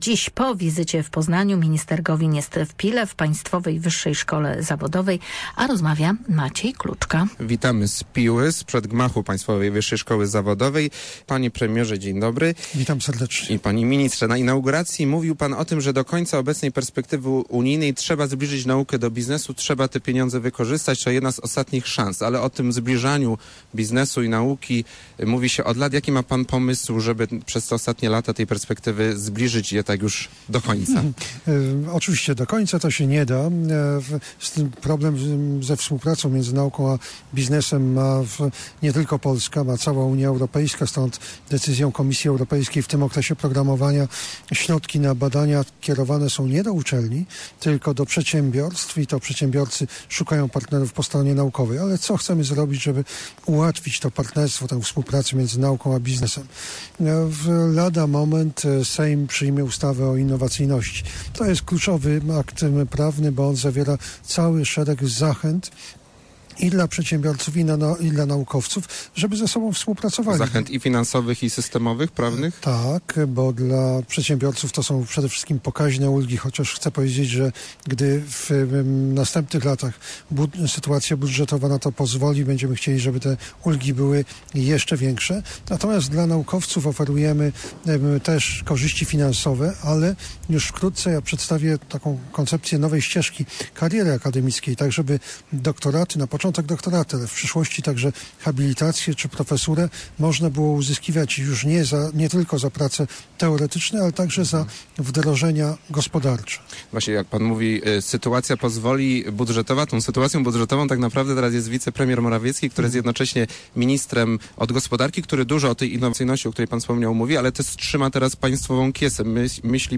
Radio Merkury było na rozpoczęciu roku akademickiego w Pile.